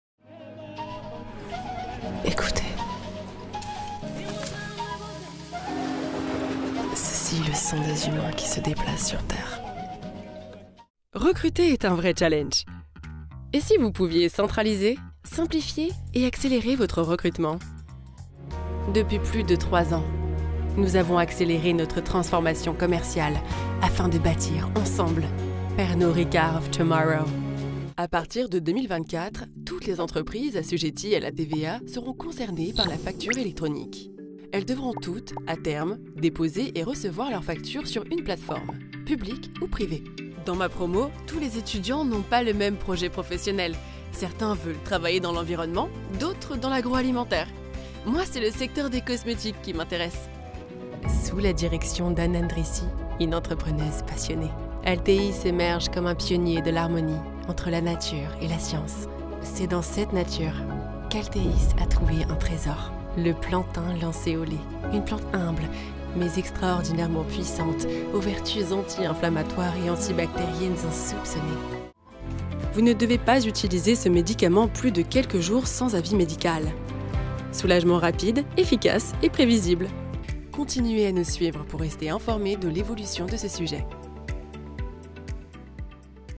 Corporate Demo
I have a medium deep voice with a large voice palette and many crazy characters at the service of your fairy tails, video games and commercials.